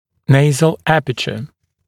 [‘neɪzl ‘æpətjuə][‘нэйзл ‘эпэтйуэ]носовая апертура